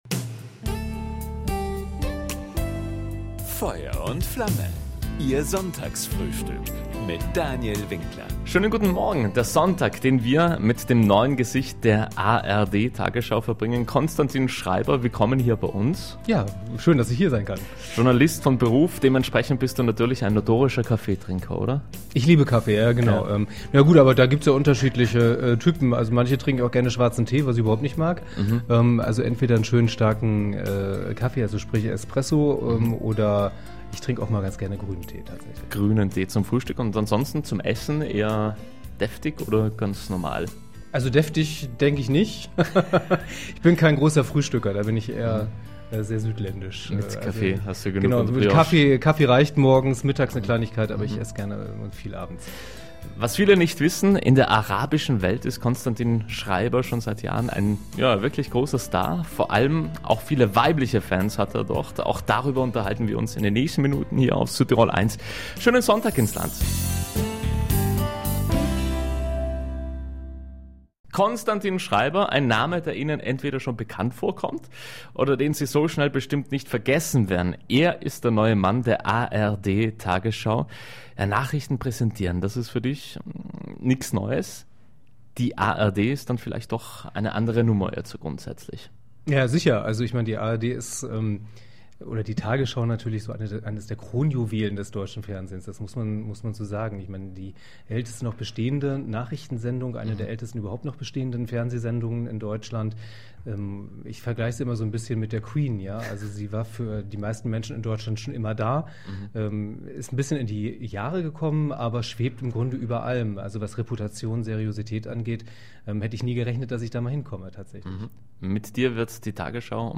Den beruflichen, aber auch den privaten Schreiber gab es an diesem Wochenende im Südtirol 1 Sonntagsfrühstück.